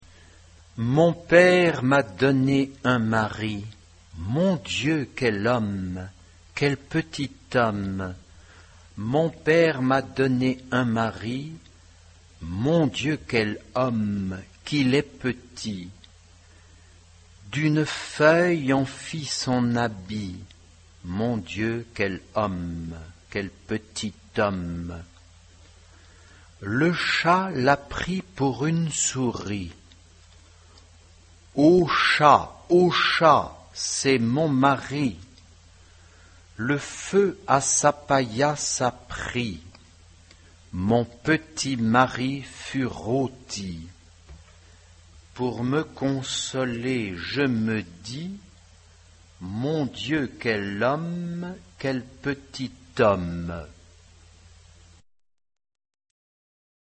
Mon père m'a donné un mari, Harmonisé par Michel Sepulchre. SAH (3 voix mixtes).
Populaire.
Chanson.